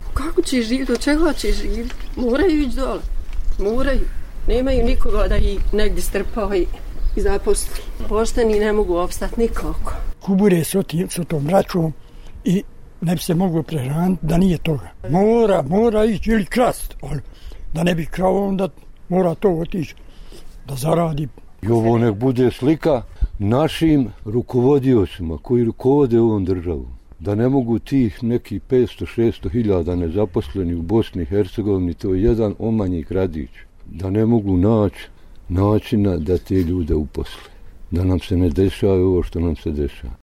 Komenari mještana Gradišća